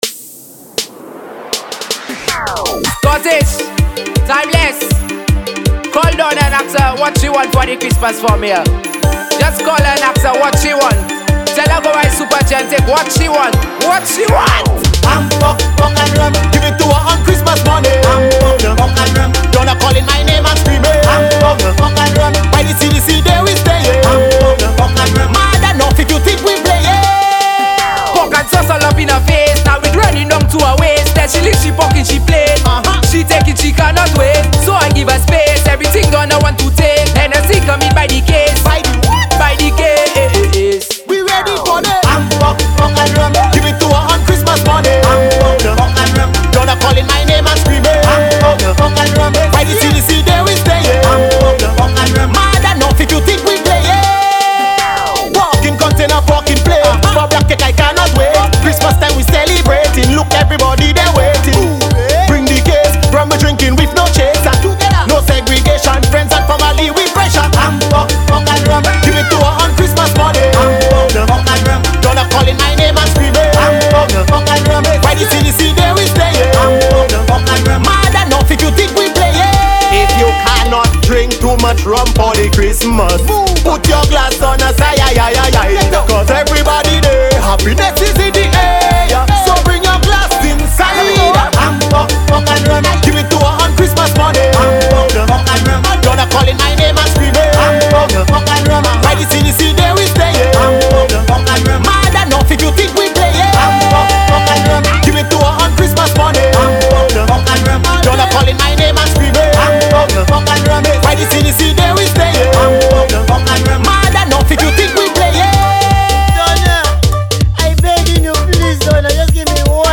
Saint Lucian music.
Genre: Fast-Fusion, Blues